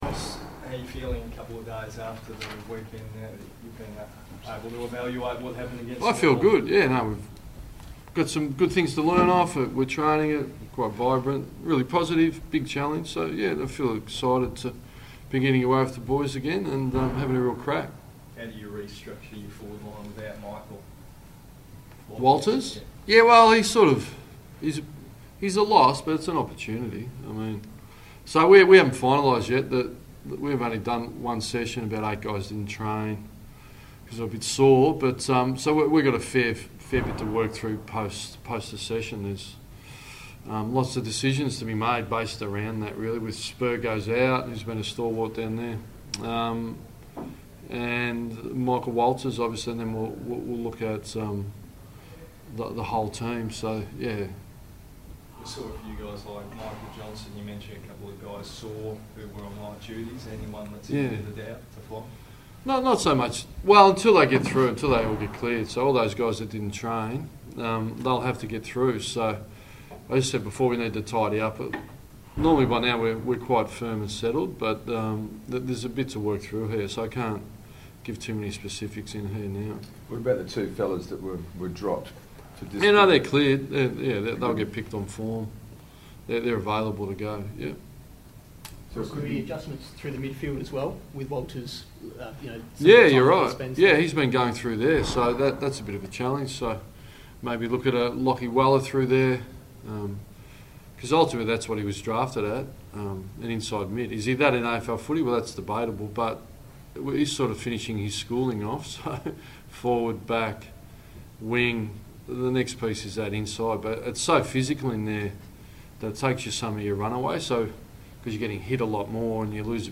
Ross Lyon press conference - 27 July